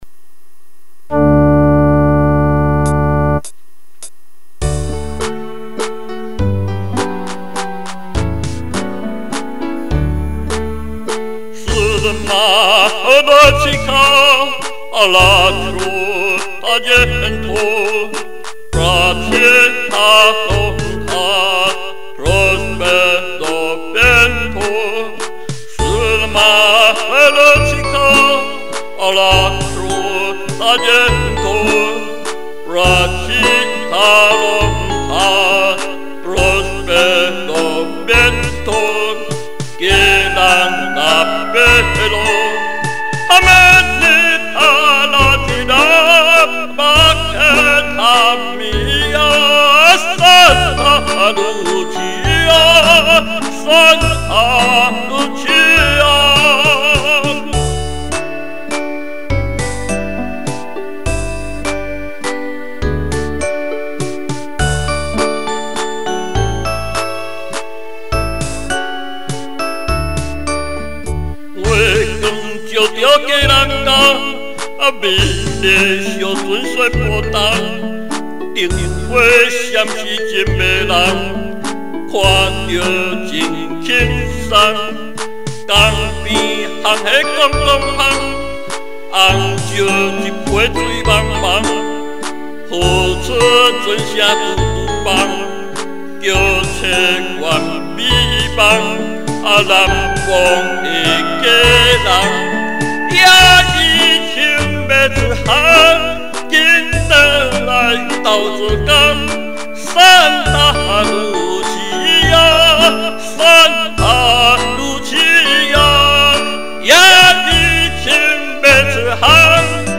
Key C or D  3/4  Tem=102
保留意文，第二段詞才用台語歌唱。
而在結尾前增加一小節高音歌唱「雞籠」。